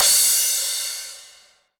soft-hitfinish.wav